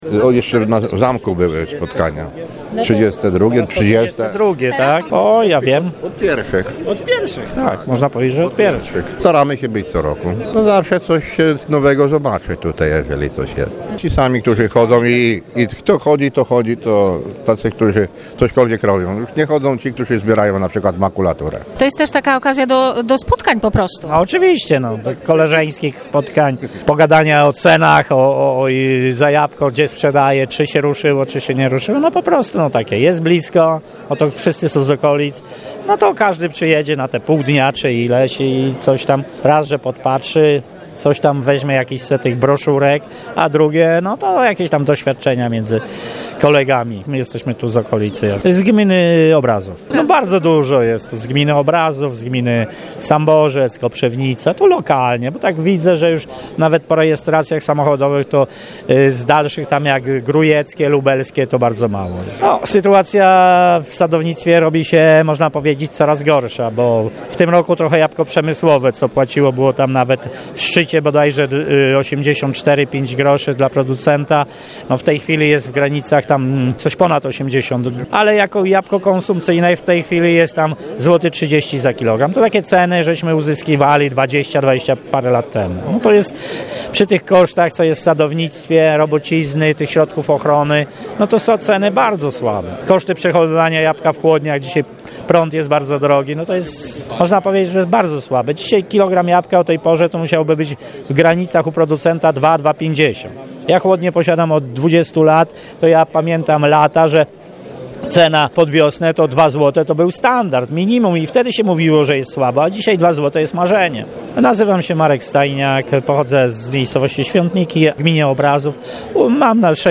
’- Niskie ceny, przesycony towarem rynek, napływ produktów z zagranicy oraz wyludnianie się wsi – na takie problemy zwracali uwagę sadownicy odwiedzający 32 Spotkanie Sadownicze w Sandomierzu: